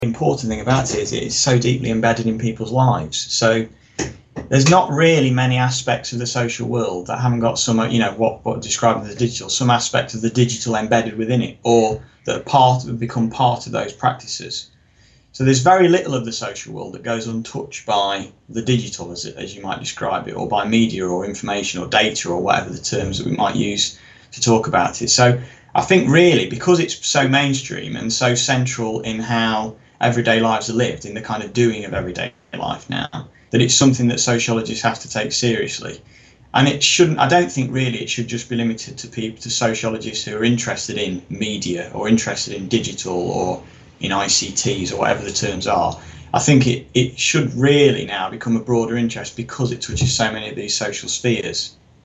How will sociology cope with digital data? An interview